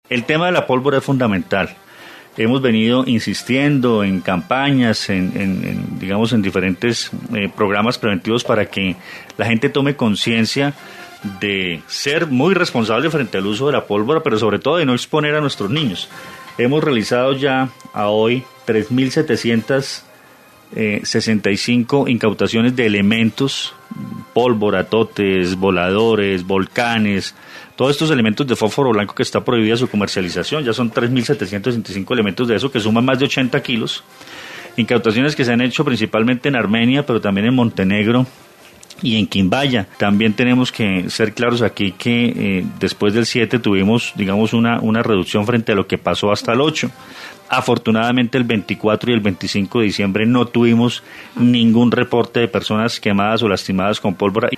Coronel Luis Fernando Atuesta, comandante de la Policía del Quindío